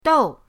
dou4.mp3